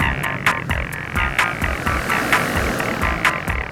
Good Morning 129-C.wav